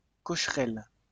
Cocherel (French pronunciation: [kɔʃʁɛl]